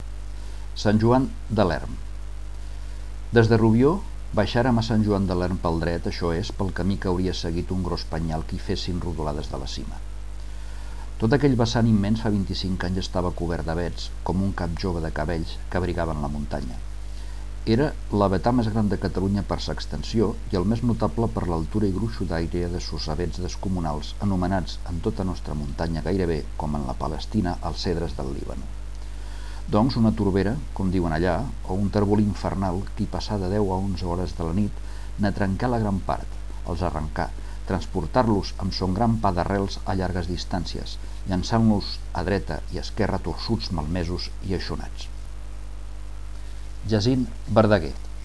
lectura